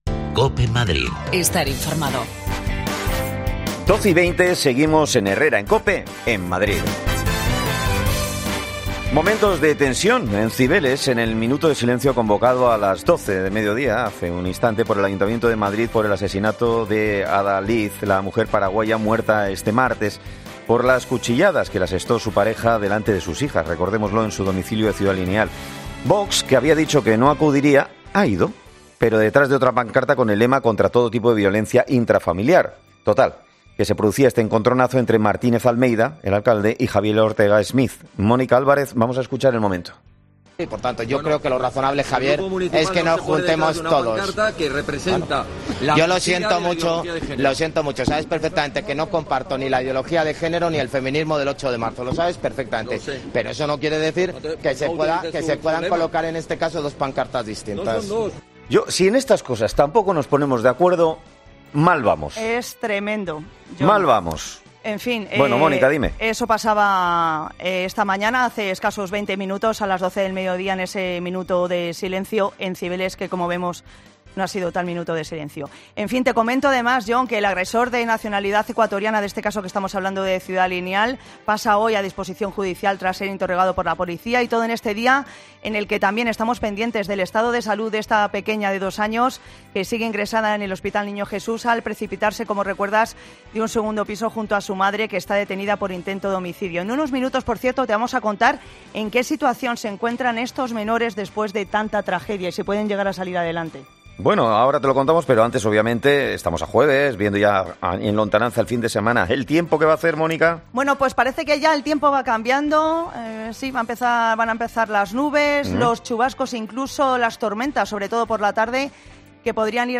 Muere a manos de su pareja delante de sus hijas. Hablamos con un experto que nos diga cómo lo pueden superar
Las desconexiones locales de Madrid son espacios de 10 minutos de duración que se emiten en COPE, de lunes a viernes.